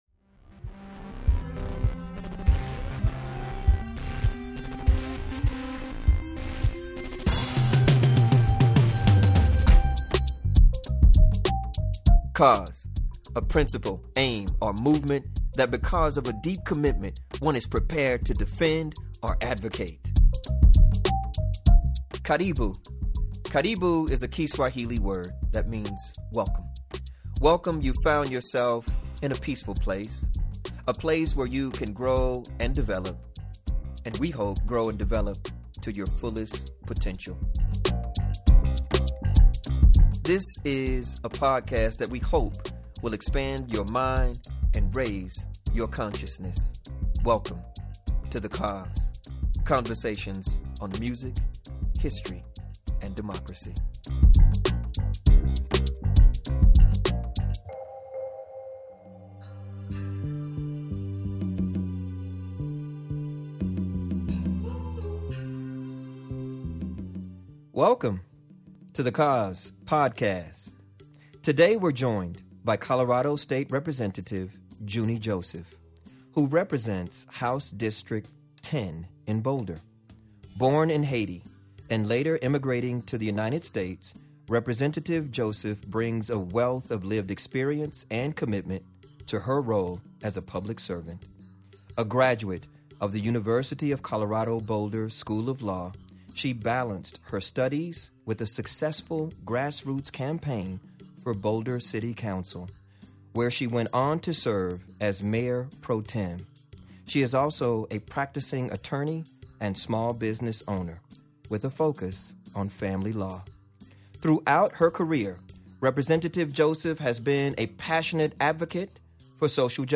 Episode 21: A Conversation with State Rep. Junie Joseph
Website Exclusive: LISTEN TO THE FULL UNEDITED VERSION